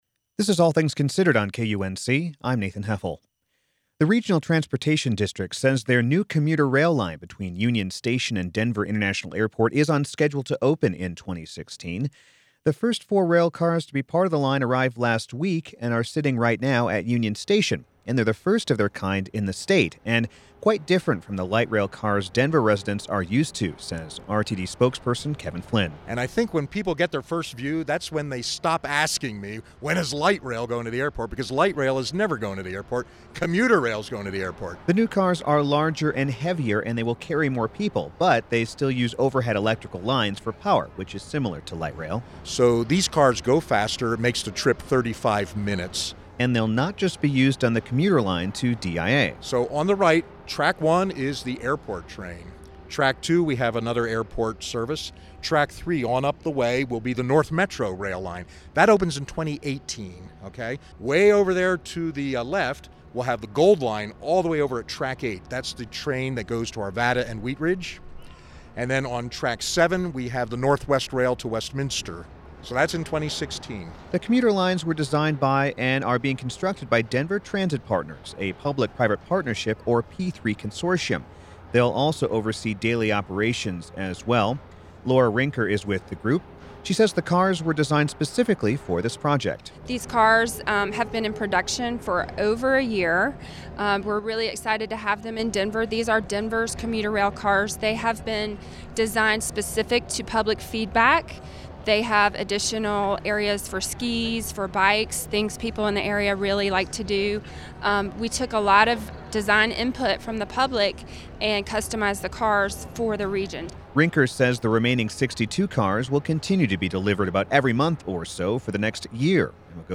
Audio: News report on Denver’s new East Commuter Rail Line